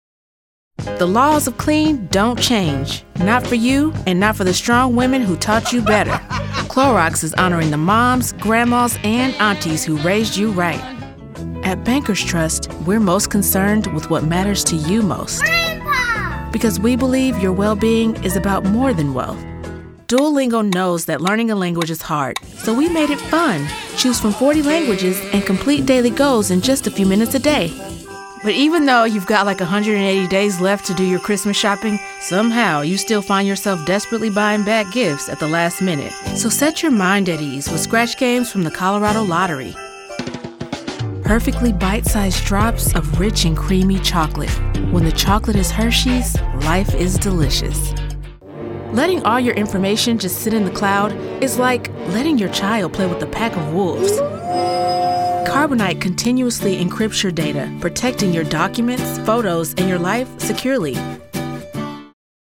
Resume and Demos